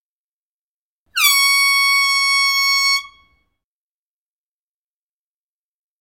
Football Air Horn | Sneak On The Lot